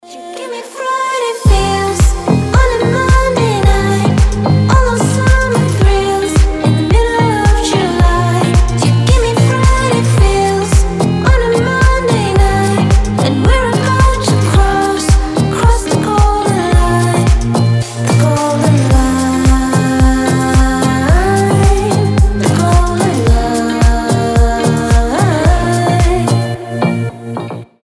deep house
мелодичные
приятные
красивый женский голос
теплые
Chill